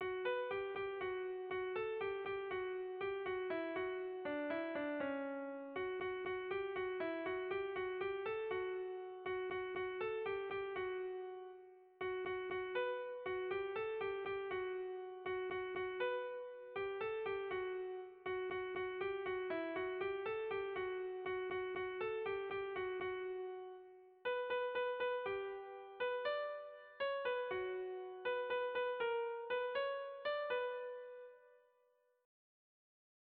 Tragikoa
Hamarreko handia (hg) / Bost puntuko handia (ip)
A-B-C-D-E